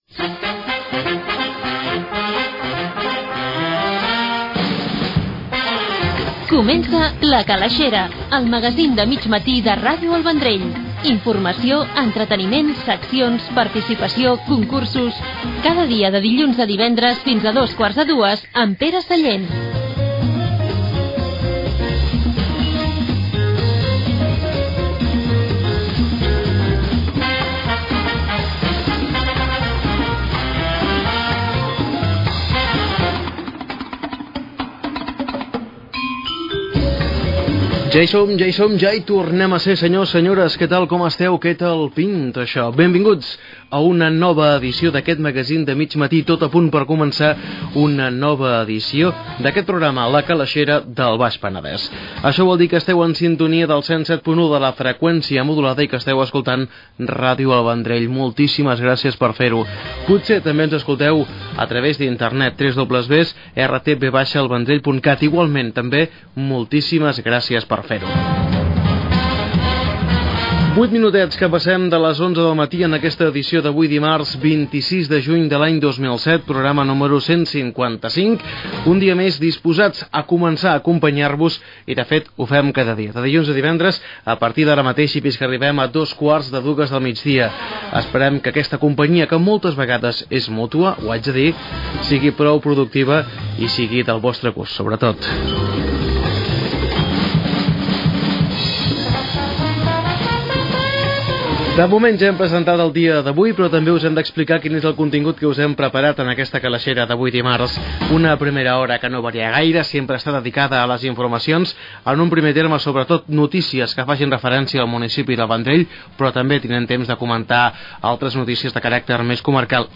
Careta del programa, presentació, hora, data, sumari, formes de participar al programa
Entreteniment
FM